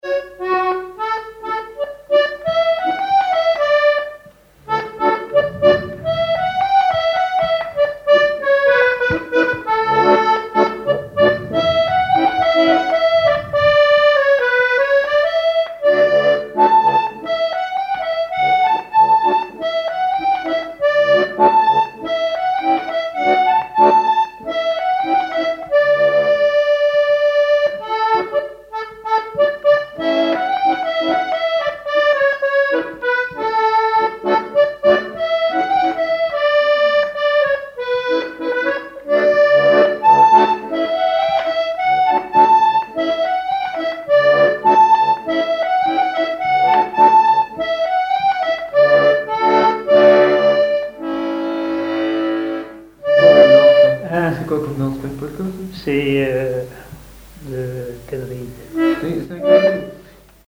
danse : quadrille
Répertoire sur accordéon diatonique
Pièce musicale inédite